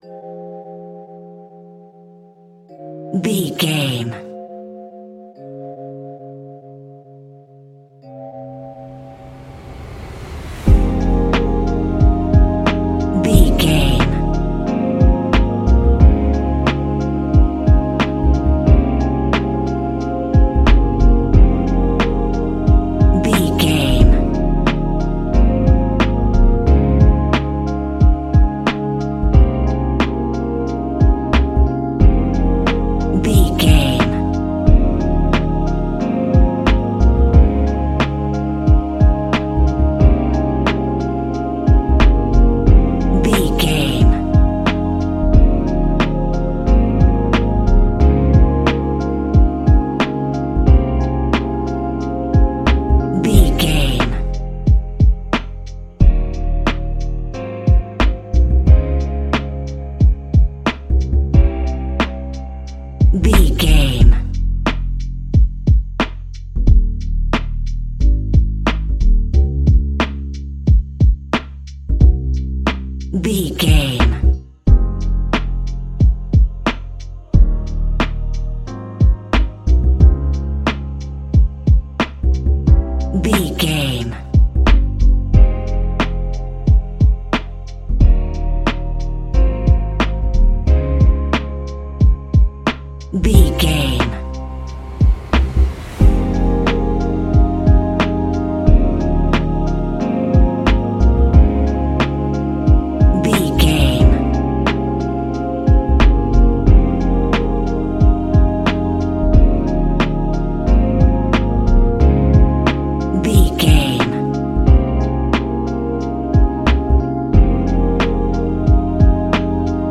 Ionian/Major
F♯
laid back
Lounge
sparse
new age
chilled electronica
ambient
atmospheric
morphing
instrumentals